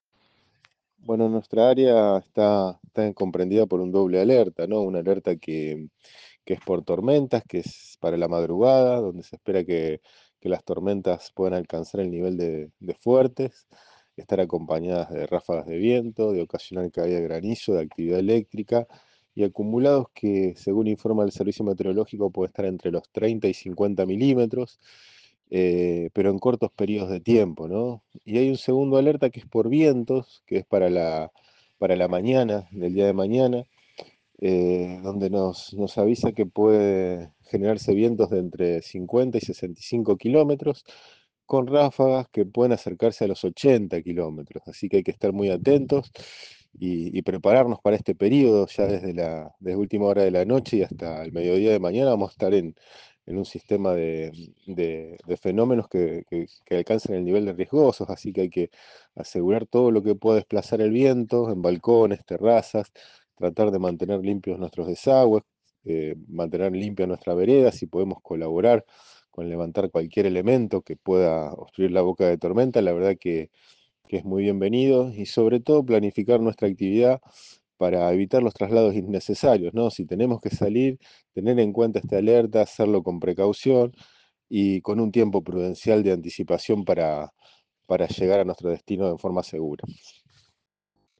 El móvil de LT3 habló con Gonzalo Ratner, Director General de Gestión de Riesgo y Protección Civil Municipalidad de Rosario quien confirmó que existe una doble alerta meteorológica para la noche de este martes y la mañana del miércoles incluso hasta el mediodía con tormentas fuertes, vientos y hasta la posibilidad de granizo.